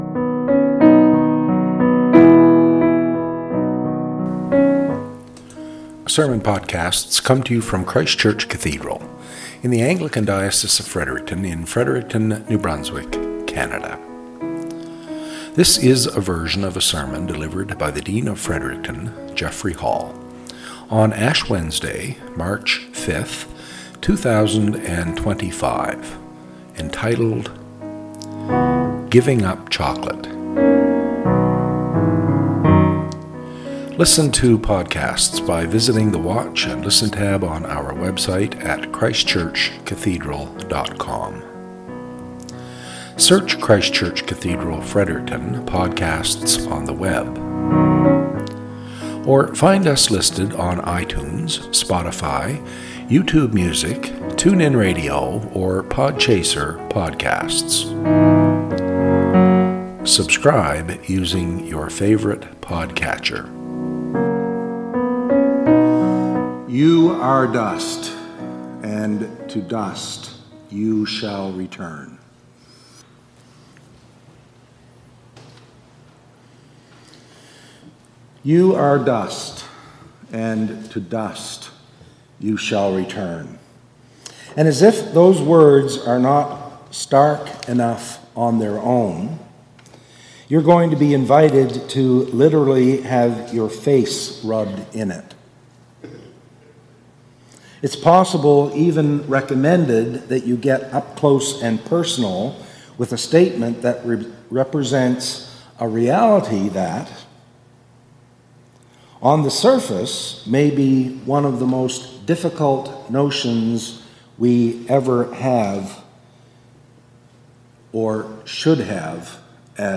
Cathedral Podcast - SERMON -